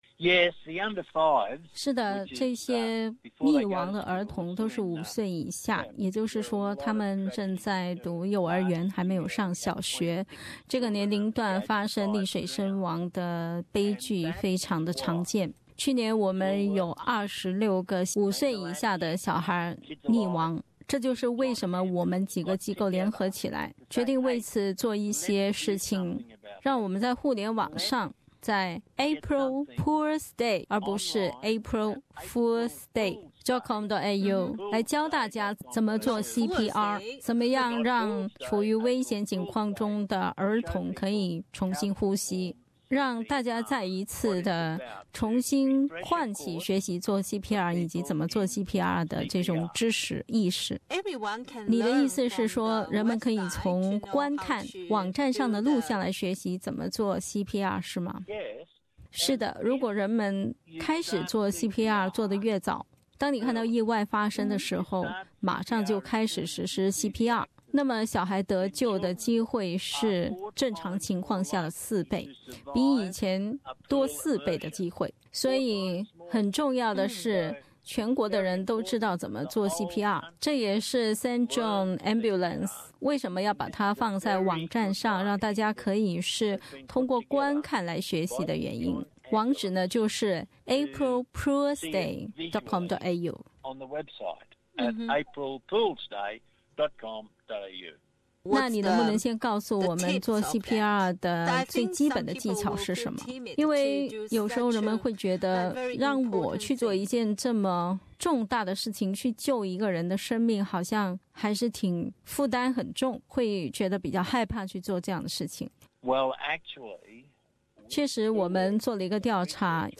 下面，我们就来听听这位澳大利亚游泳安全大使的介绍，以便知道为什么你要去学CPR吧。